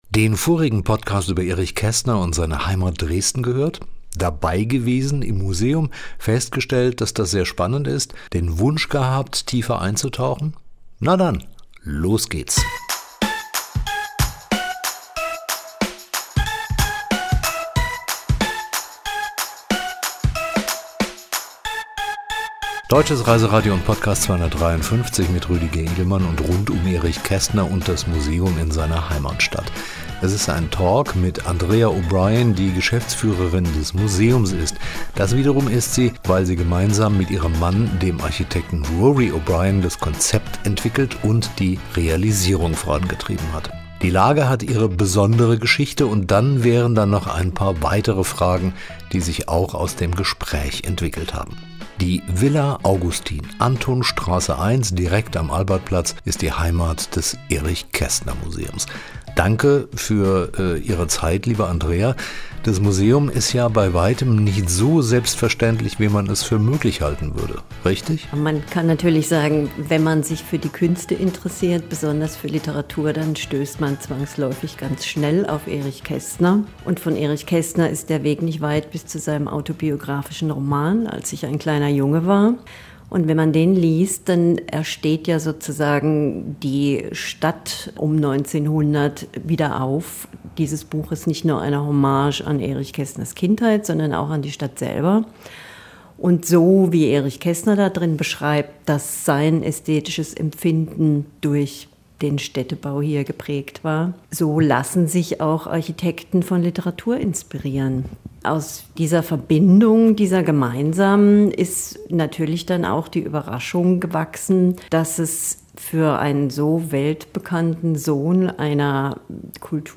DRR253_Talk_Erich-Kaestner-Museum.mp3